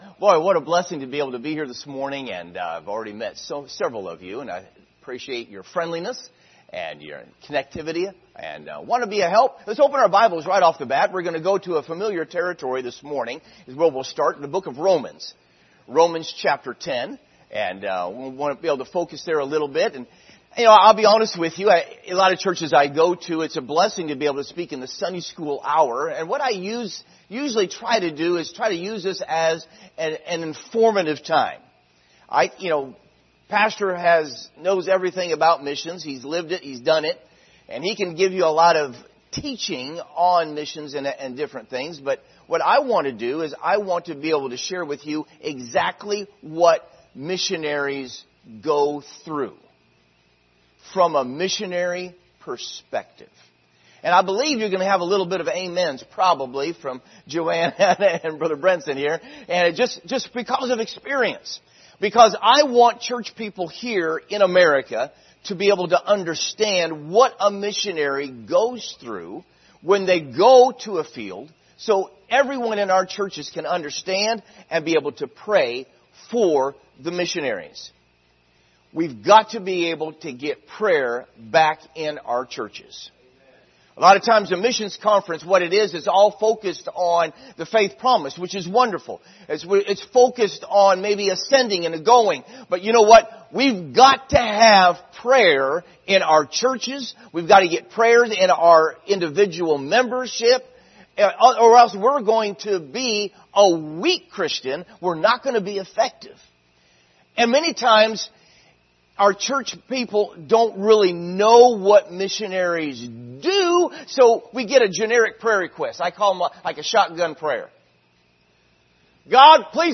Speaker: Missionary
Service Type: Sunday School Hour